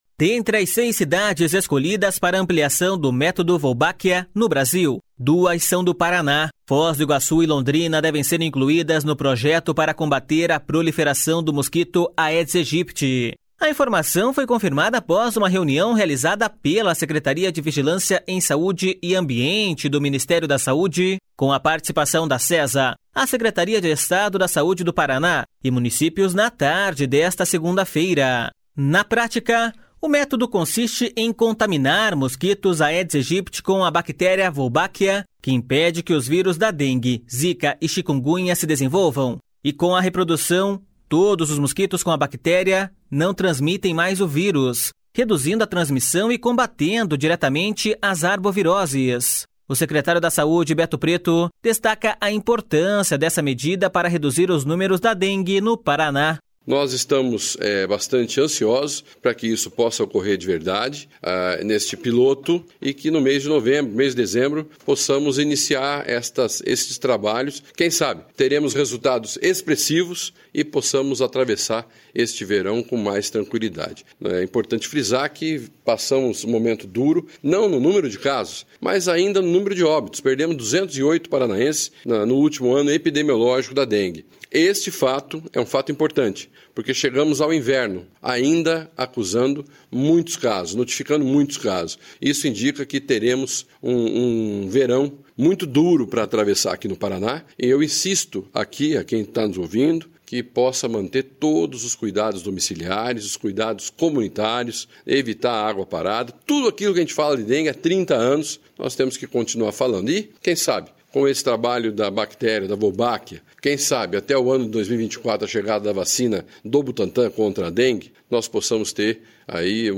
O secretário da Saúde, Beto Preto, destaca a importância dessa medida para reduzir os números da dengue no Paraná.// SONORA BETO PRETO.//